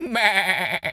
goat_baa_stressed_hurt_07.wav